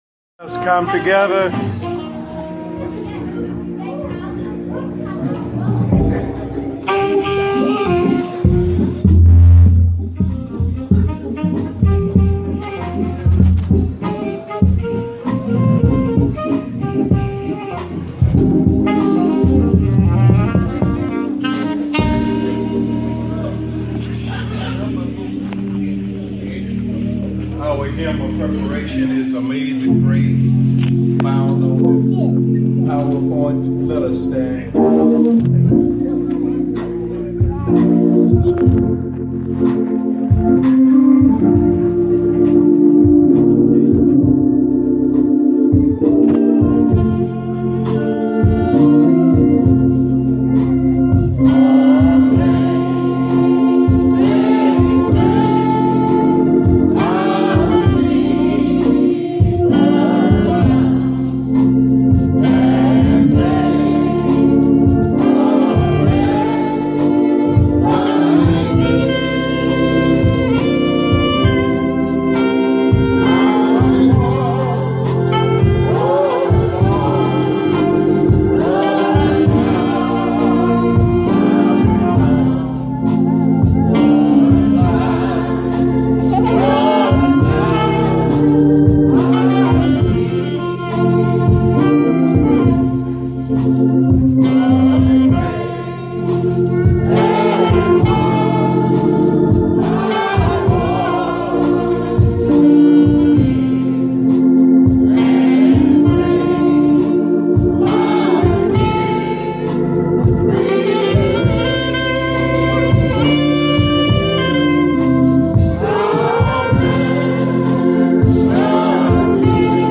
Every Sunday we bring a message of hope.